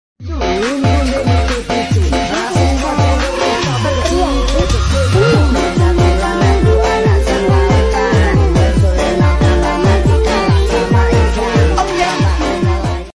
Jadi kangen dengan interior bus sound effects free download